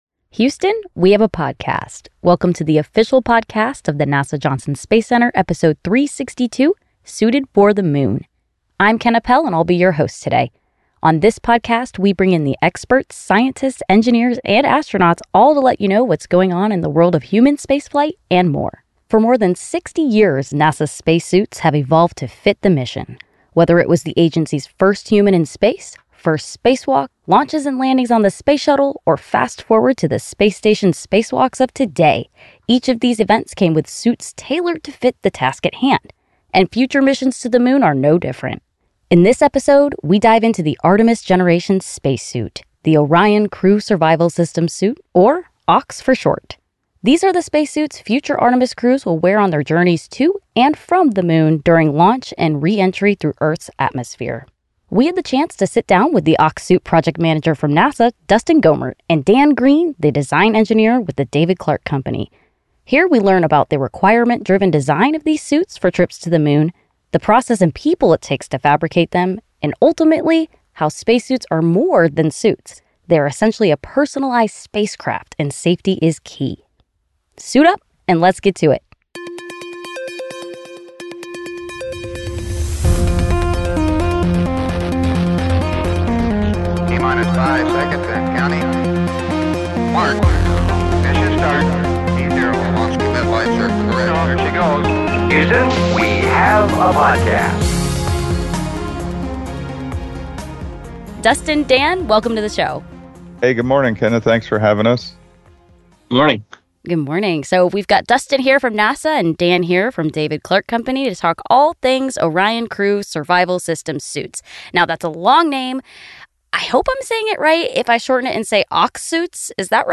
A NASA project manager and a design engineer discuss the Orion Crew Survival System Suit that future Artemis crews will wear on their journeys to and from the Moon.